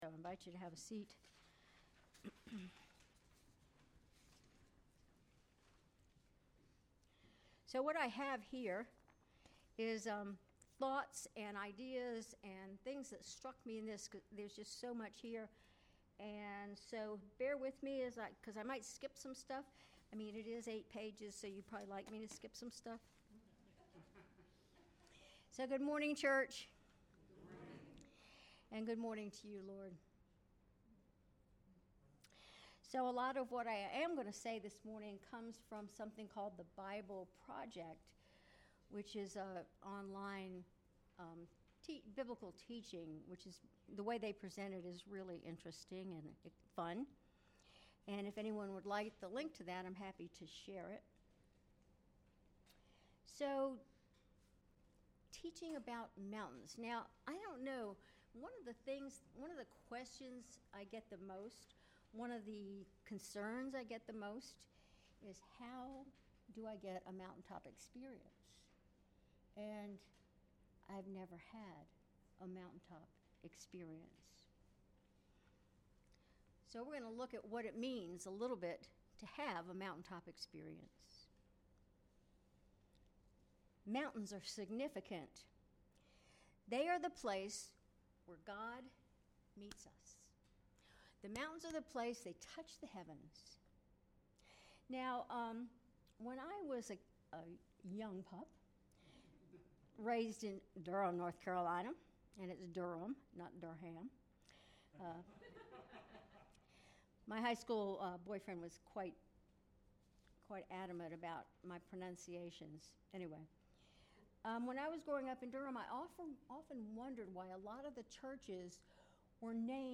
Sermon March 2, 2025